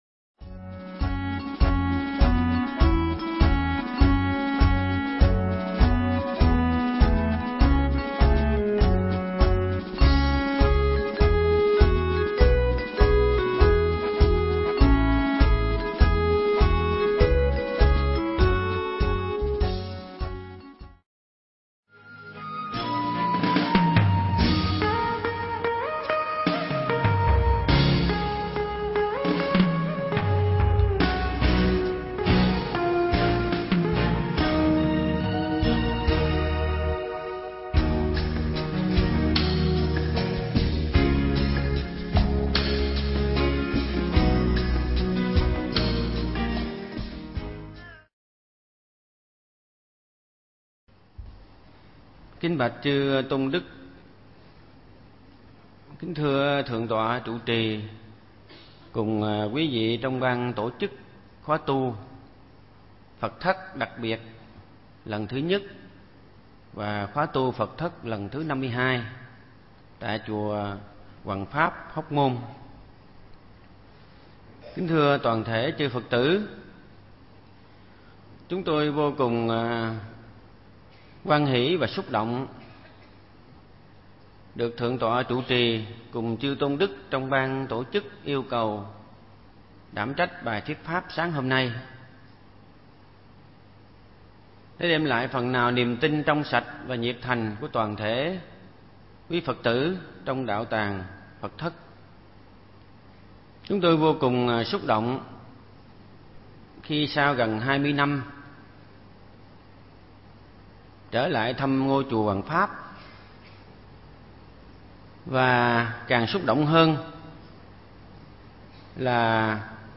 Nghe Mp3 thuyết pháp Chữ Hiếu Trong Kinh Tạng Pali
Pháp âm Chữ Hiếu Trong Kinh Tạng Pali